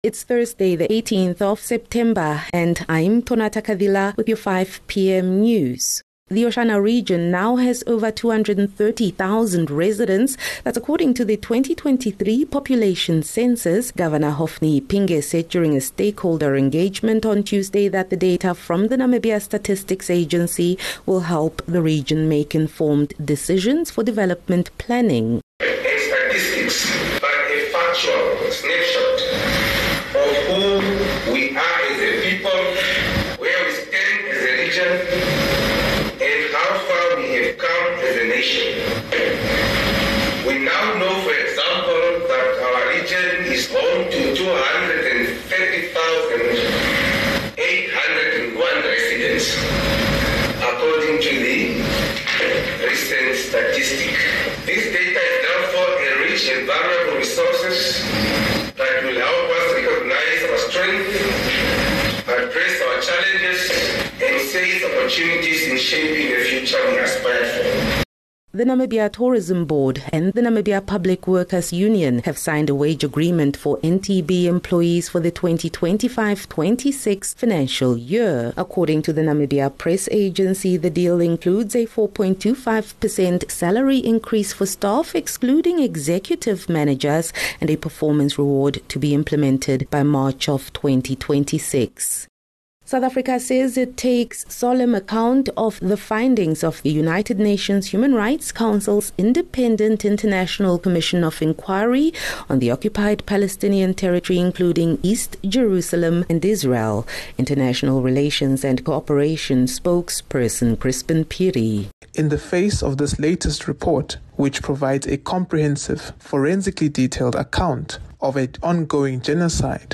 18 Sep 18 September - 5 pm news